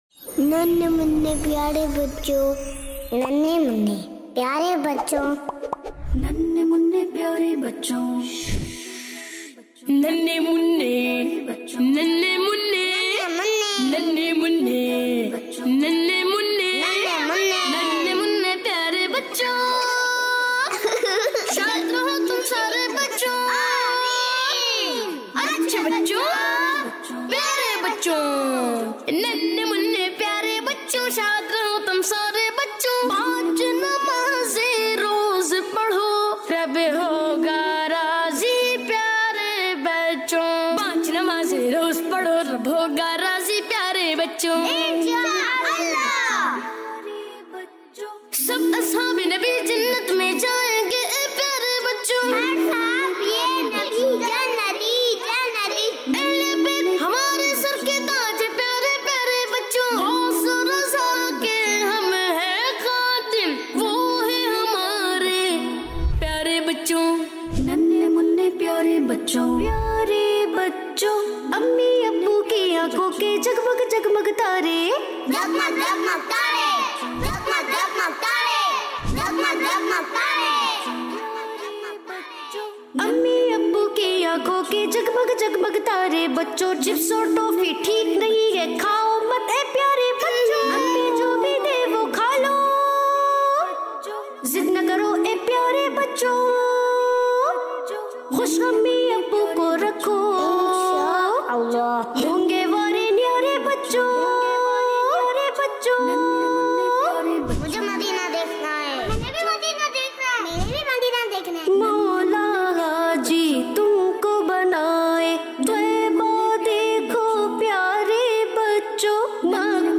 Poem - Nannhay Munnay Piyaray Bachay